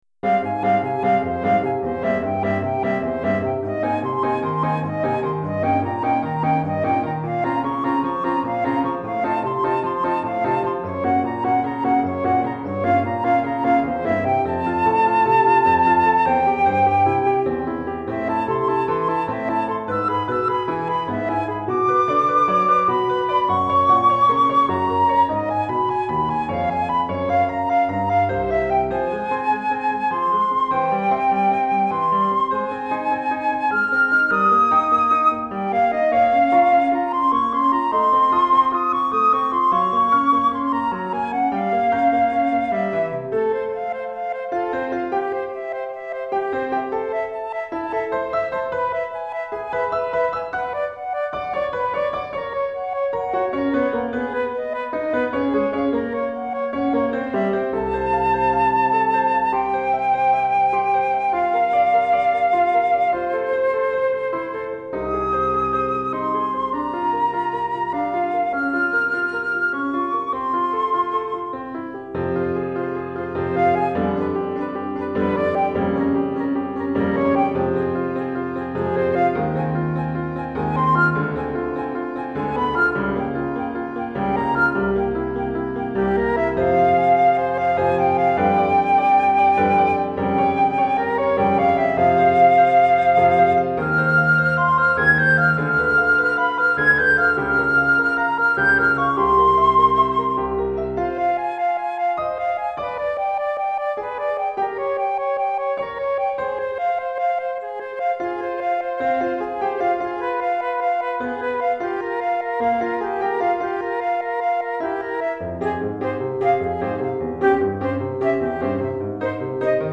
Etude pour Flûte traversière - Flûte Traversière et Piano